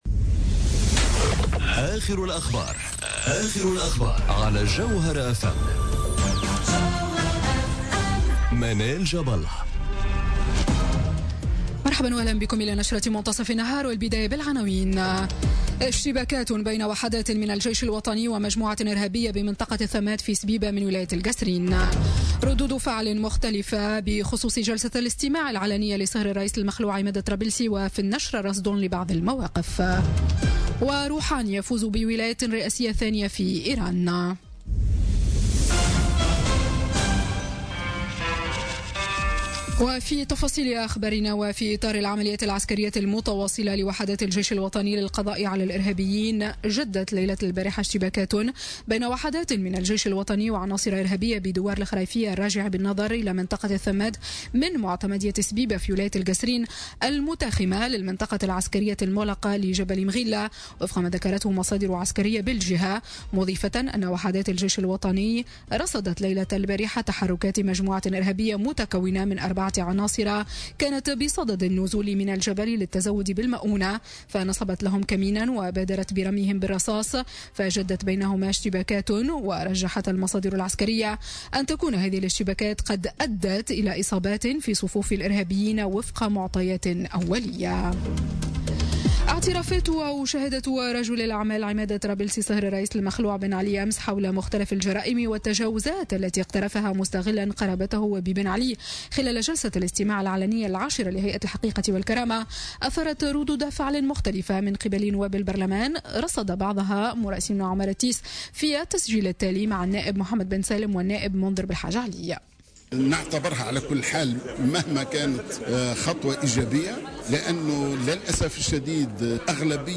نشرة أخبار منتصف النهار ليوم السبت 20 ماي 2017